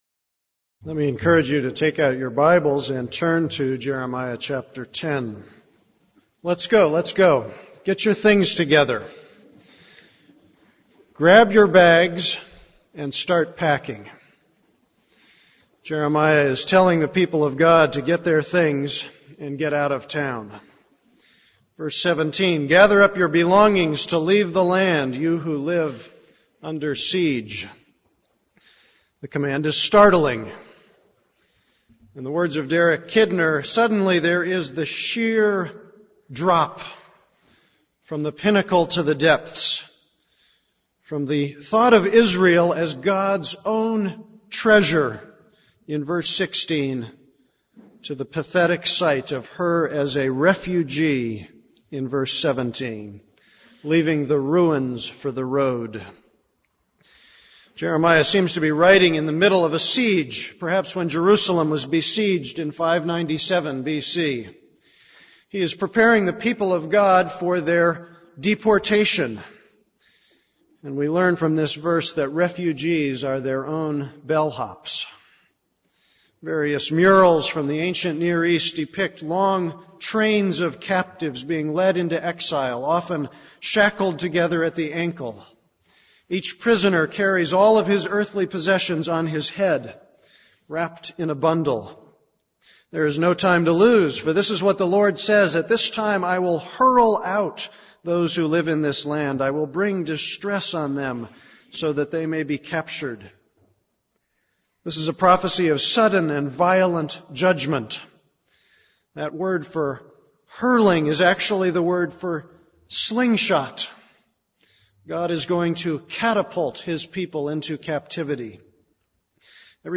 This is a sermon on Jeremiah 10:17-25.